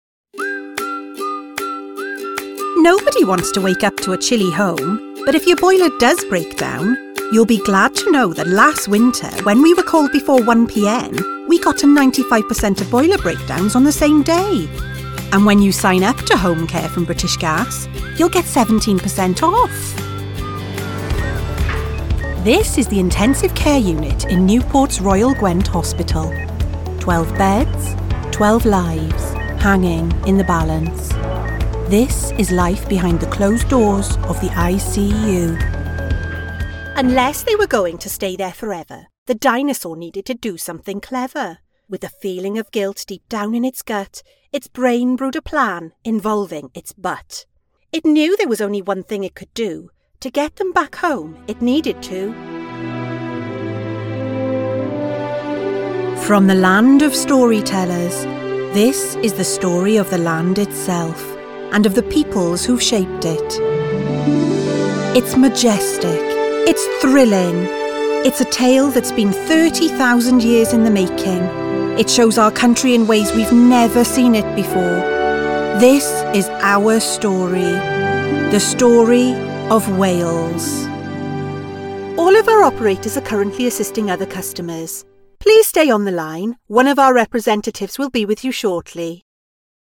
Welsh Accent Showreel
Female
Warm